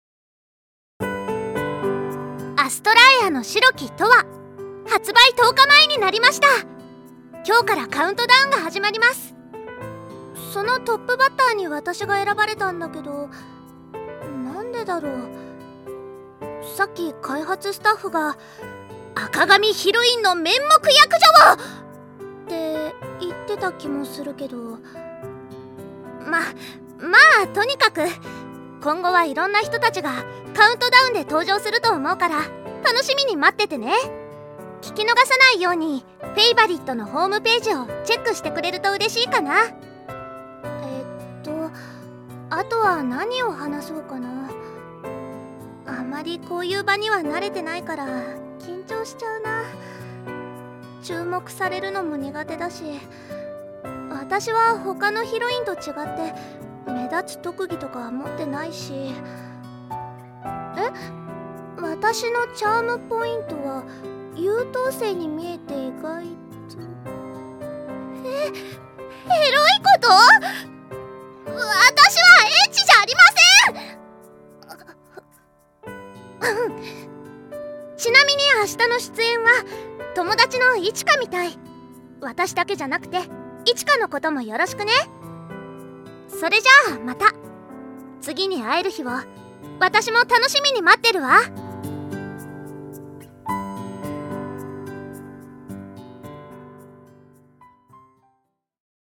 『アストラエアの白き永遠』 発売10日前カウントダウンボイス(落葉)を公開